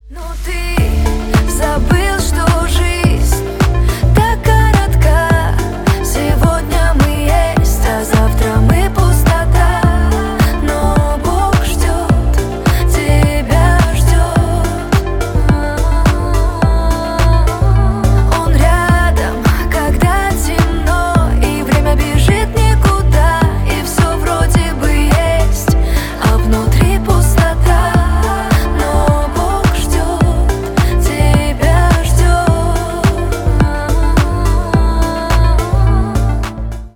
душевные
поп